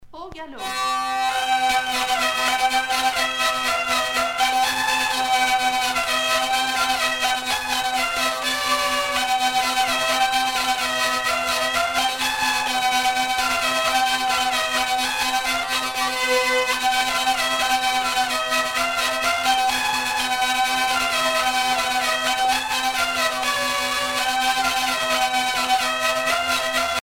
danse : quadrille : grand galop
Pièce musicale éditée